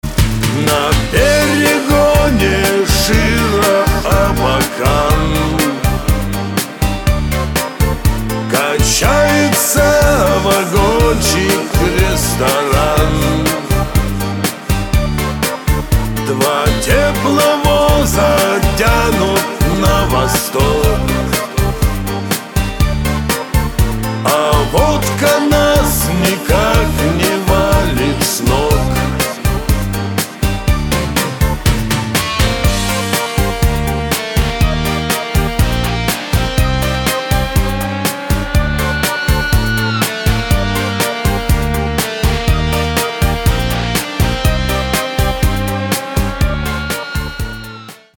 мужской вокал
спокойные
русский шансон